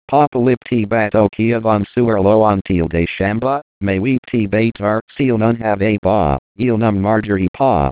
Les paroles ont été créés par synthèse vocale (Text-to-Speech Synthesis),